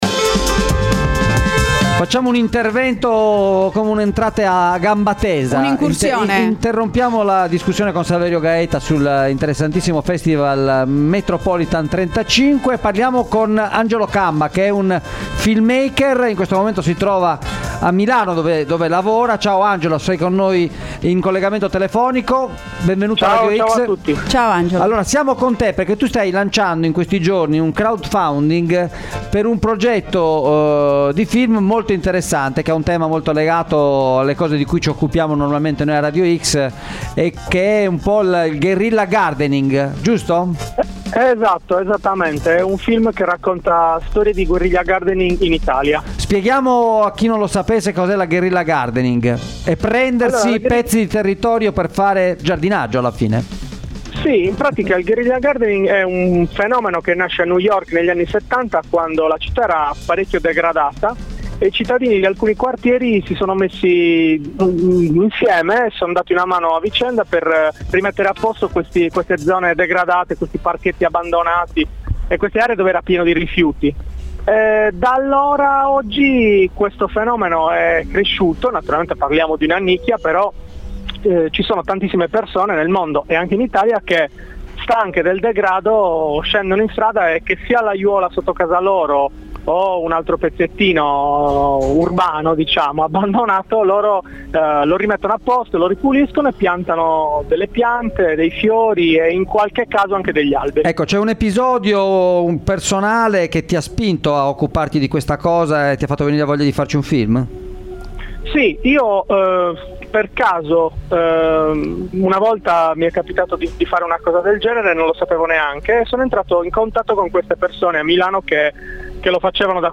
Ne abbiamo parlato a Extralive mattina in collegamento